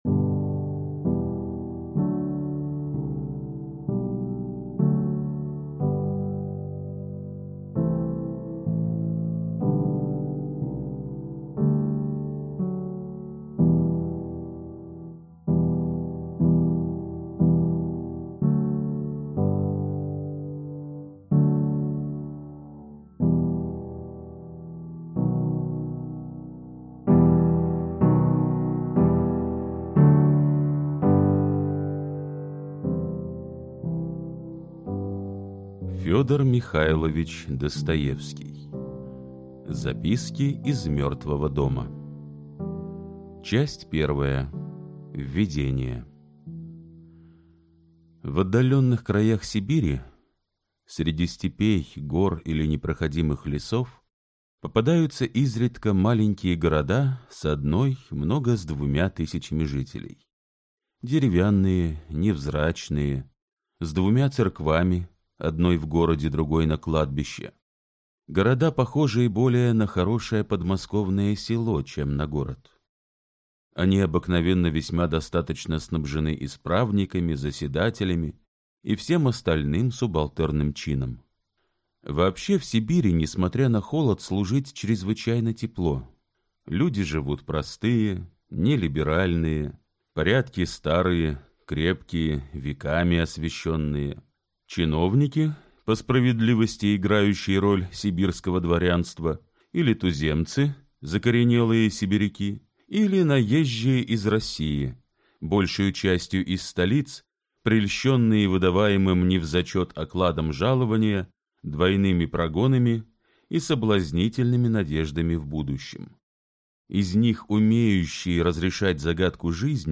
Аудиокнига Записки из мертвого дома | Библиотека аудиокниг